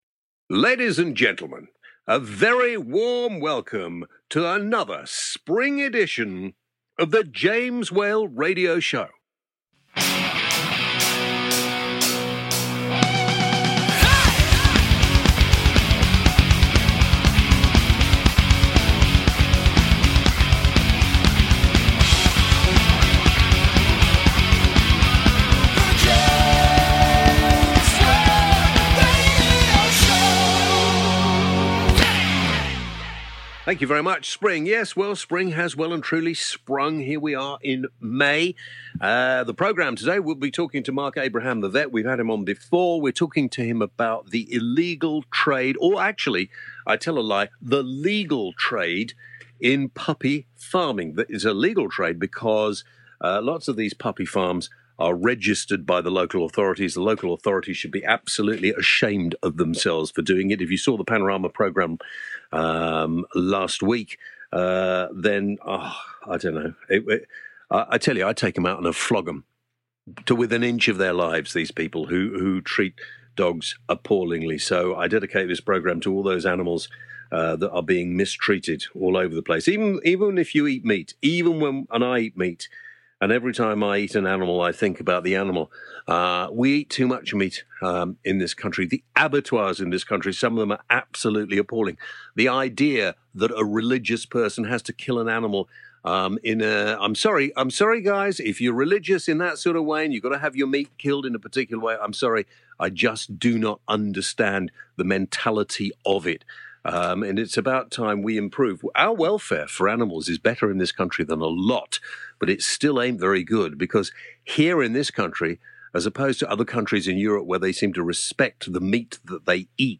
Disturbed by the appalling treatment of dogs shown on BBC’s Panorama programme James invites Marc Abraham, or ‘Marc the Vet’ as he is more commonly known as on to the show.